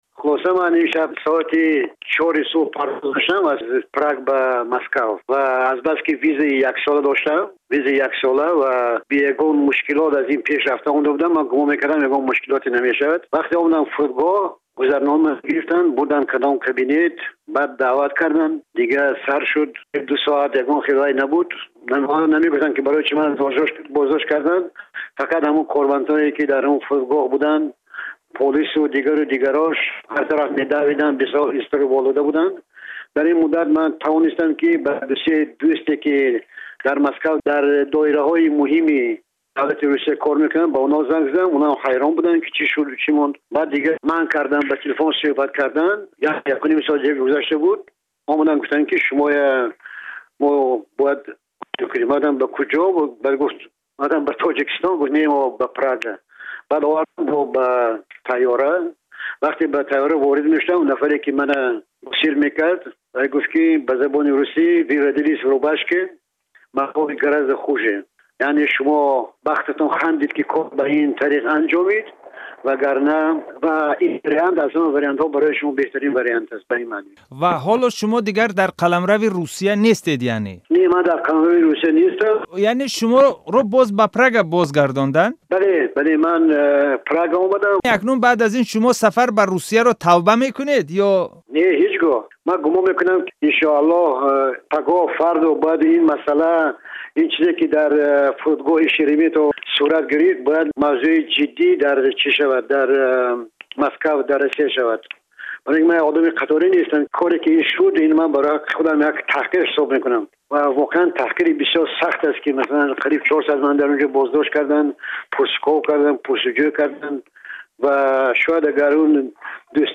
як сӯҳбати ихтисосӣ бо Радиои Озодӣ